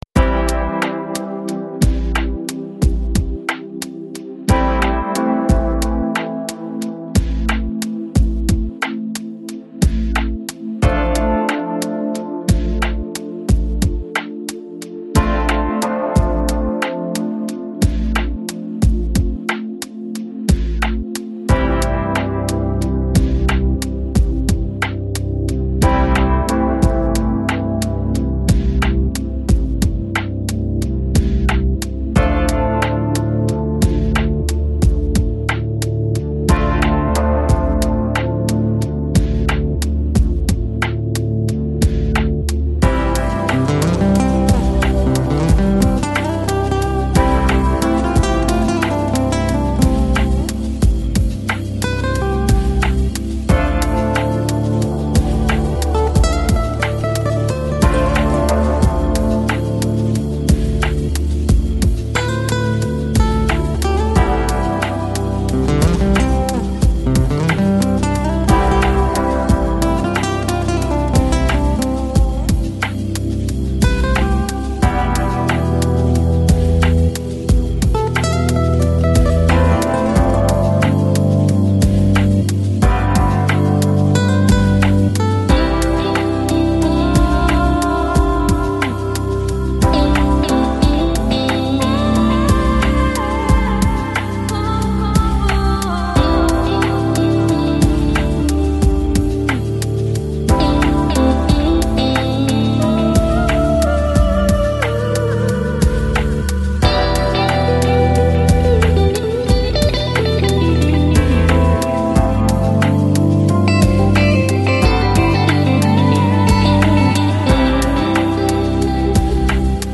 Electronic, Chill Out, Lounge, Downtempo